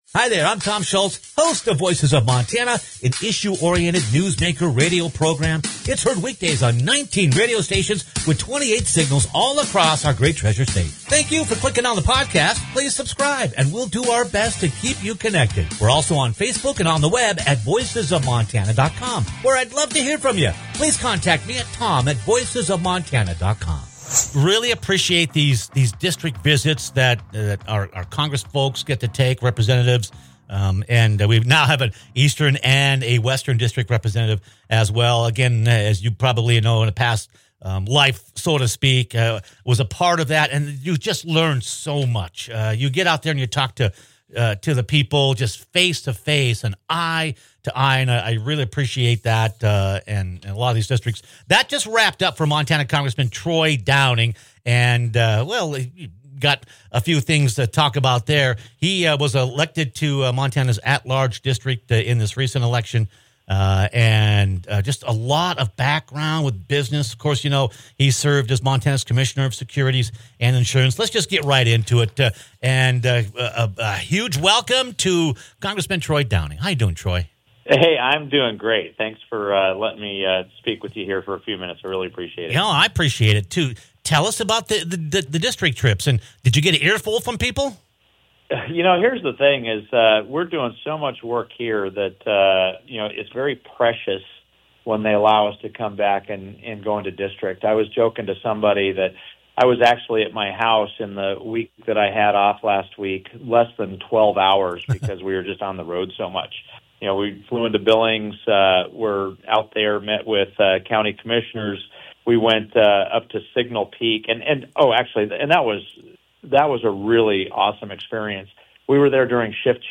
Eastern District Congressman Troy Downing hops on the program to give us an update on what's on people's minds after a week full of stops across his district.